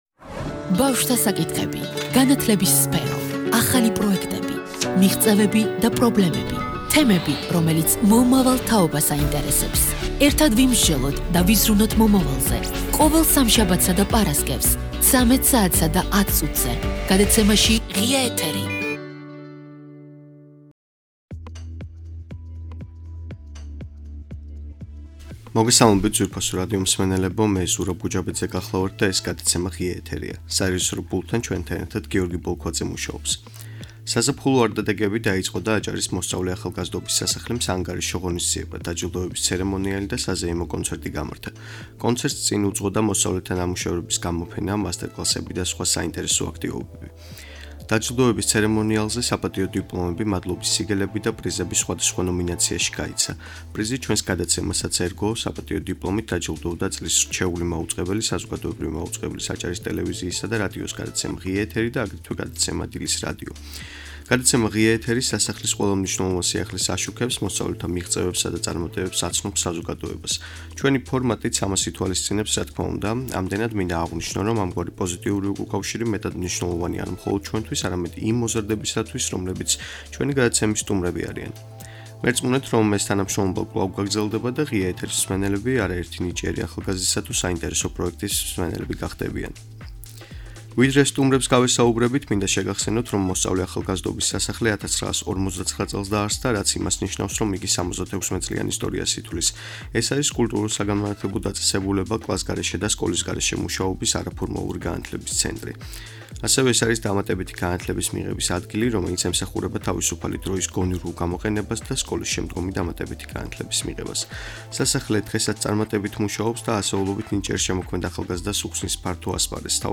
წლის რჩეულნი, დაჯილდოების ცერემონია - აჭარის მოსწავლე ახალგაზრდობის სასახლის საანგარიშო კონცერტი.